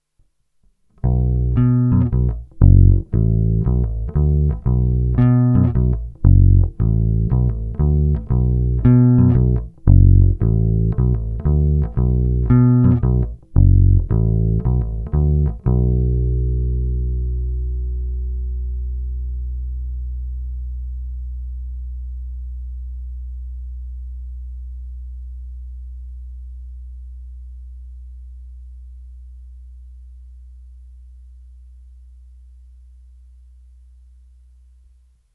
Zvuk je s těmito strunami opravdu hodně vintage a svádí ke dvěma herním stylům.
Není-li uvedeno jinak, tak nahrávky jako vždy rovnou do zvukovky, s plně otevřenou tónovou clonou a jen normalizovány.
Hráno mezi krytem a krkem